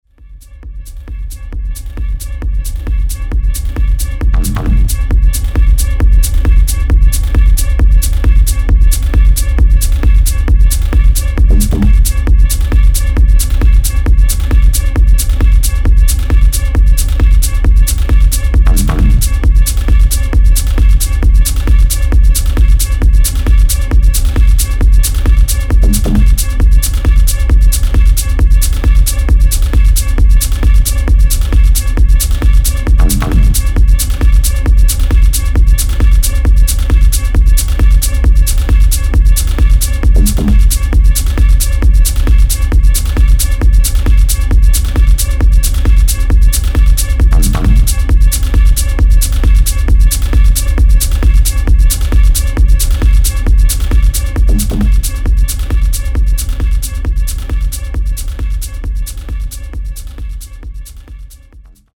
Жанр: Электронная музыка Стиль: Техно Вопрос дня- Шит контроль.....
Заметил что большинство моих свежих работ звучат слишком грязно...Да да, услышал я это на встроенной аудиокарте ( Реалдек, Система Windows) Замечаю перегрузы на нч диапазонах , вч настолько плохо отработан что его практически не слышно, середина мыленная...
Понимаю что это вовсе не студийный звук, но пока возможно только так. Кусочек трека для ознакомления .......НЧ хрипит.....Пики на Перкуссии....